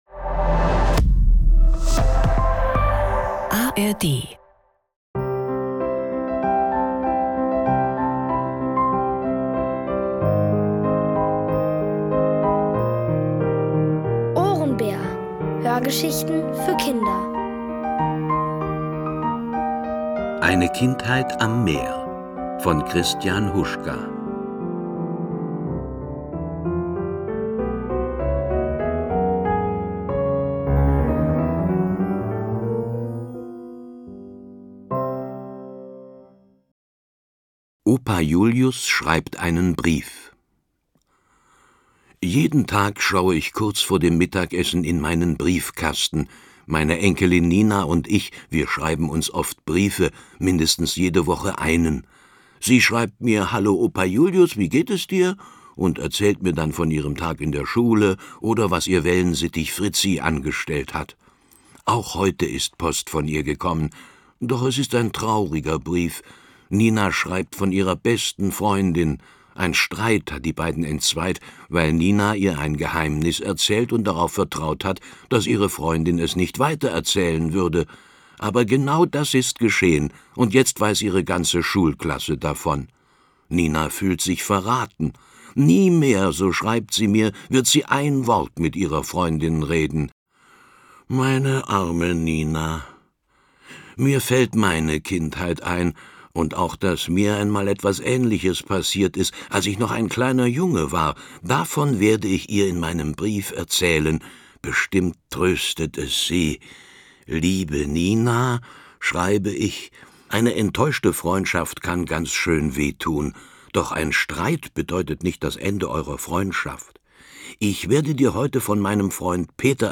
Eine Kindheit am Meer | Die komplette Hörgeschichte!